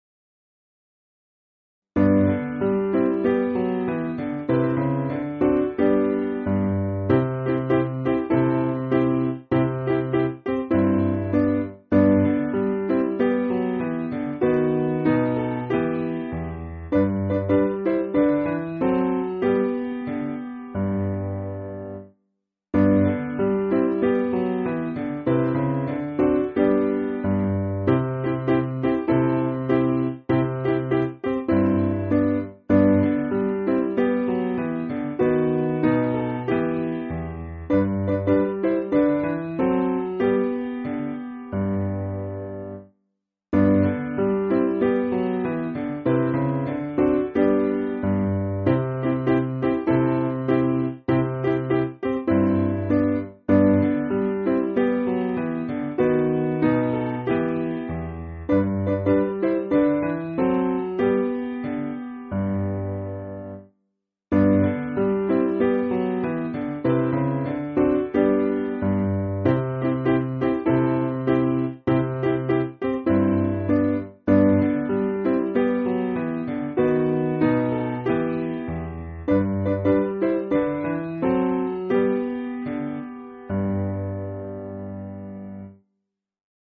Simple Piano
(CM)   4/G